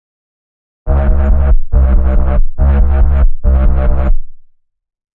描述：140 bpm的dubstep低音。由自制的贝斯样本制成（标语有更多信息）。
Tag: 循环 摆动 低音 回响贝斯